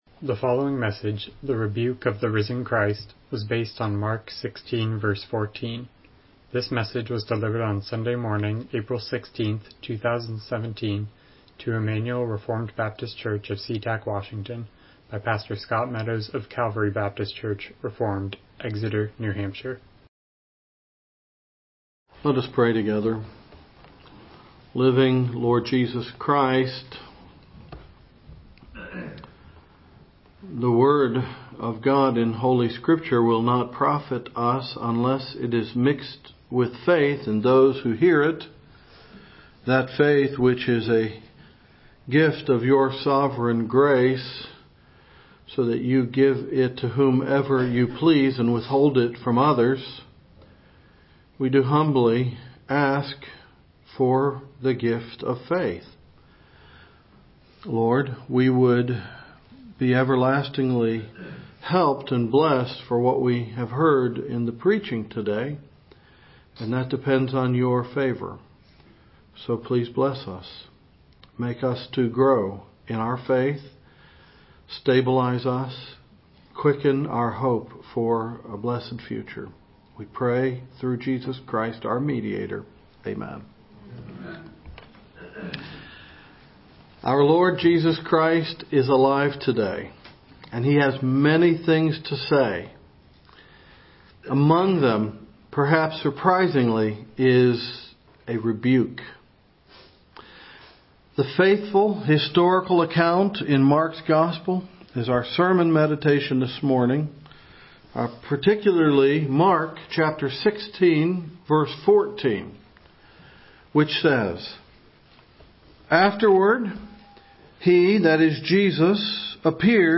Mark 16:14 Service Type: Morning Worship « Woe to the Incorrigible